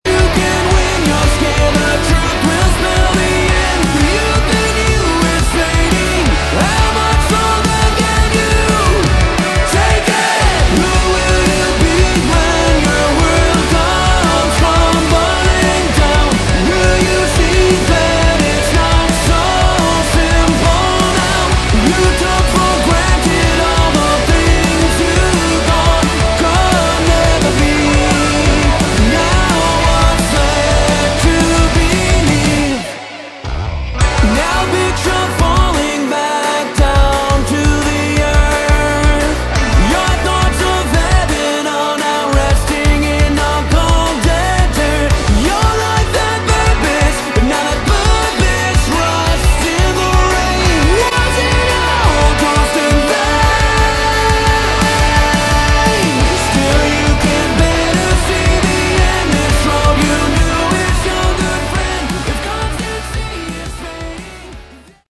Category: AOR
Vocals, Guitars, Synths
Drums, Percussion